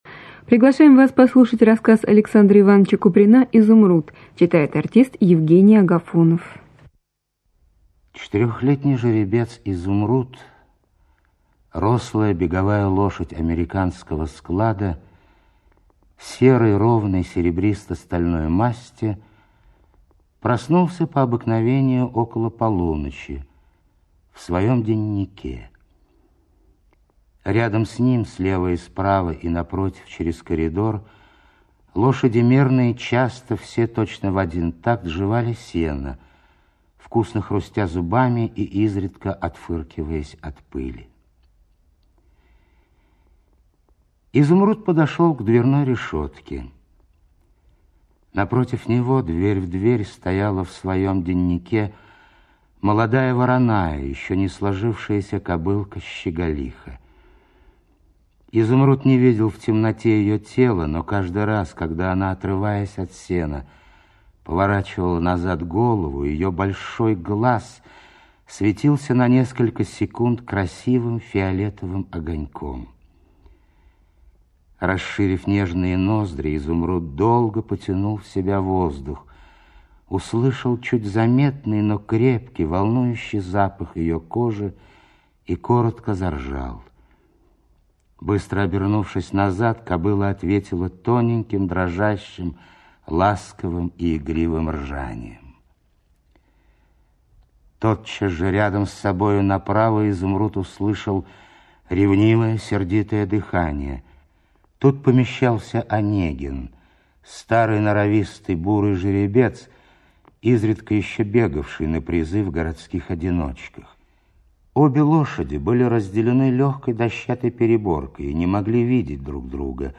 Изумруд - аудио рассказ Куприна А.И. Рассказ о жеребце Изумруде, рослом, безупречно сложенном, но погибшем из-за человеческой жадности.